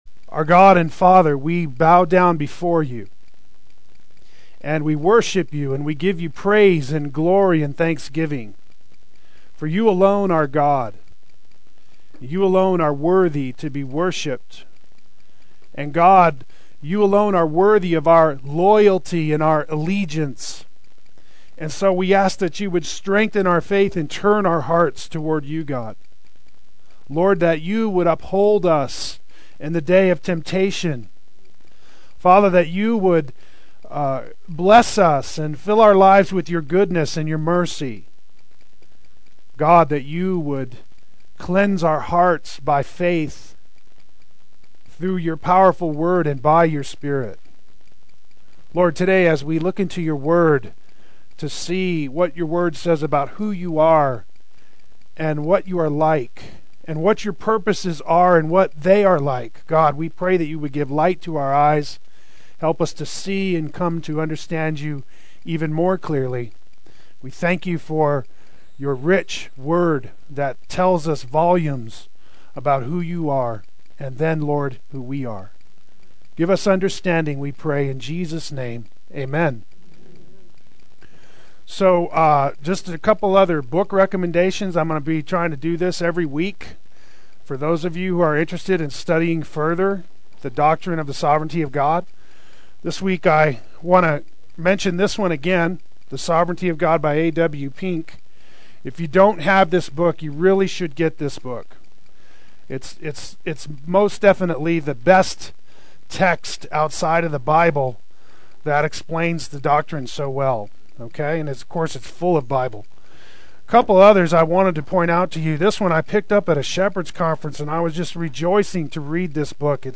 Play Sermon Get HCF Teaching Automatically.
Adult Sunday School